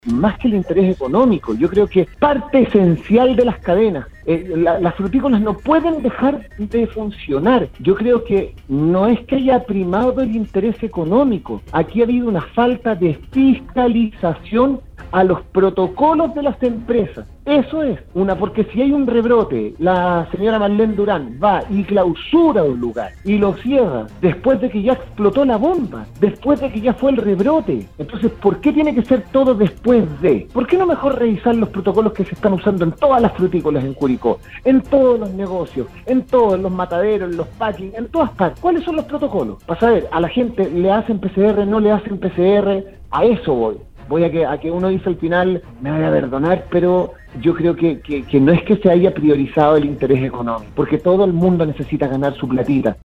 En conversación con Sala de Prensa de VLN Radio, el comunicador, Francisco Saavedra, opinó del retroceso a cuarentena para Curicó y mostró su "preocupación" por esta medida de confinamiento.